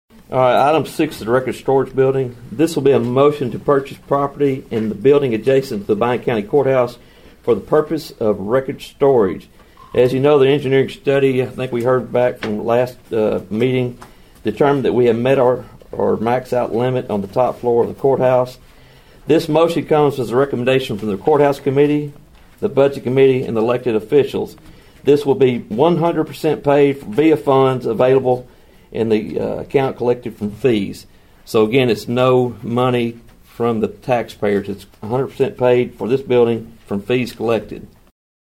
Budget Committee Chairman Sam Sinclair Jr. addressed the issue at this week’s County Commission meeting.(AUDIO)